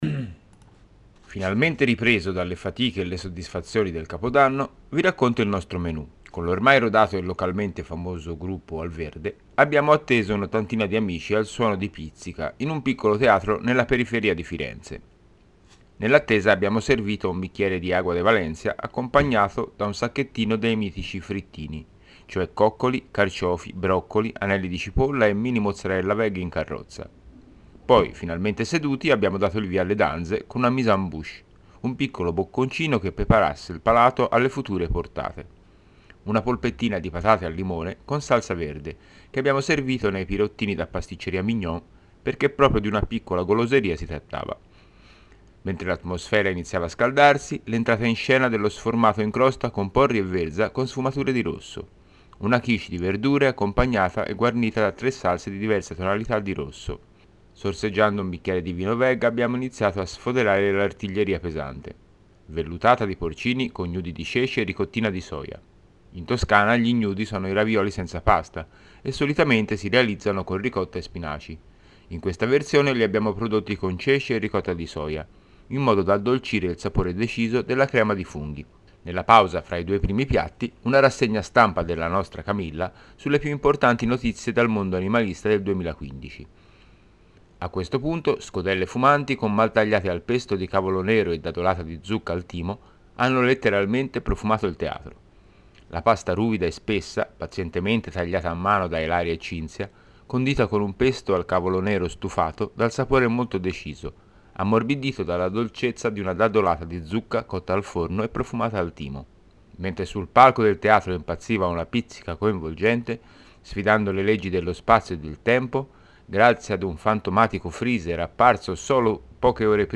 9/1/16 – Radiocronaca di un cenone di Capodanno gentile